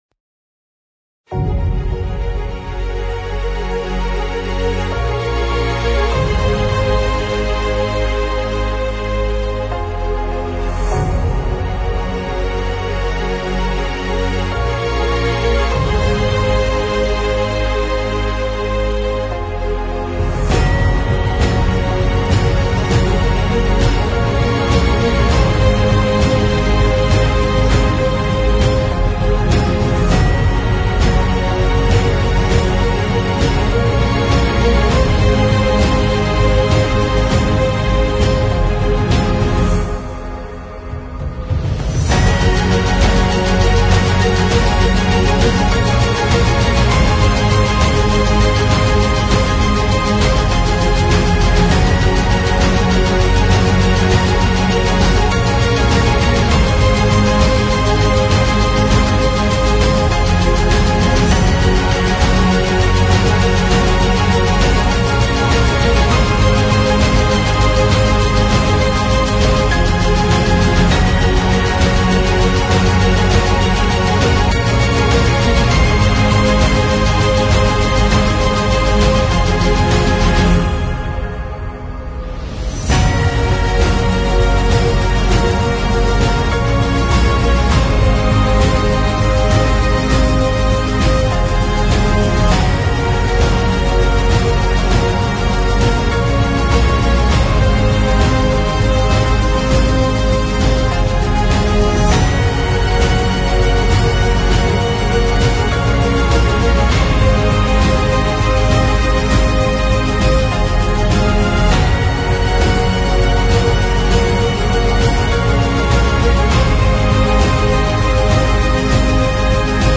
描述：这种鼓舞人心的音轨是完美的预告片，视频游戏和电影。